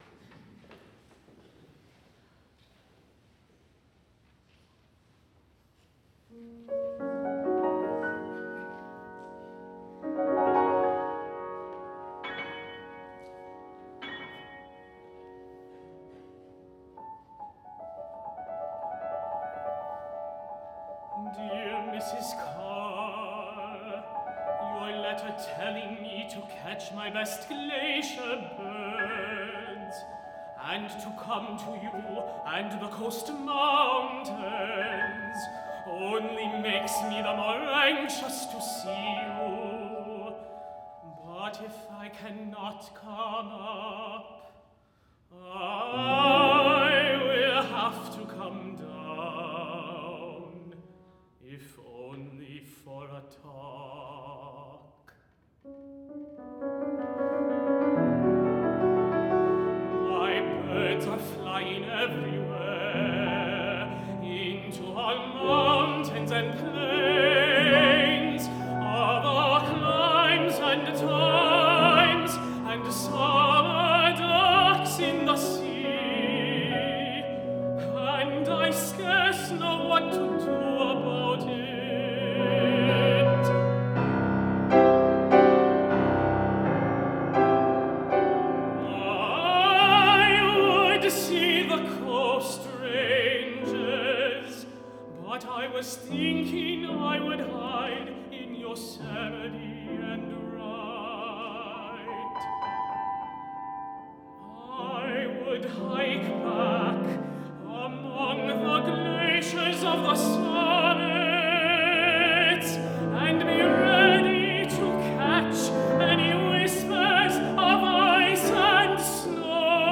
for High Voice and Piano (2014)
tenor
piano.